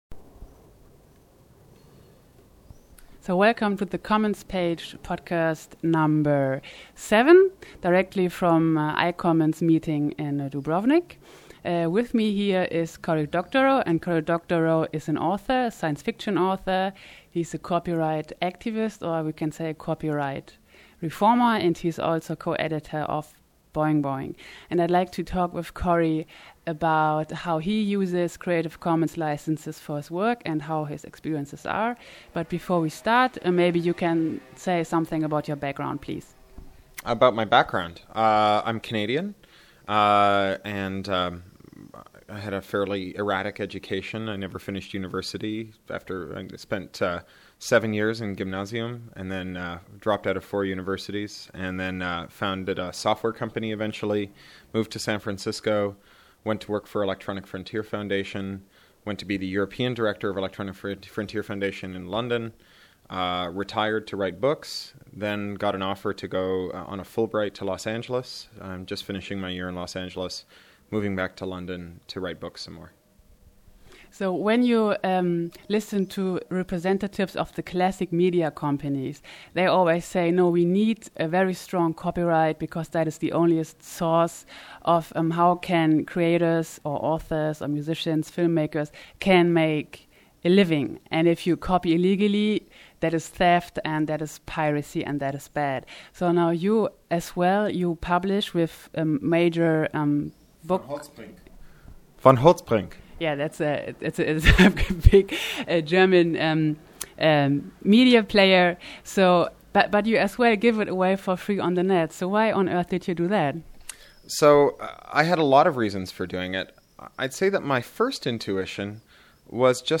iCommons: Interview mit Cory Doctorow
Next Podcast-Interview from iCommons summit: The fantasticfantasticfantastic Cory Doctorow – science-fiction-author, copyright activist, co-editor of Boing Boing – talked about: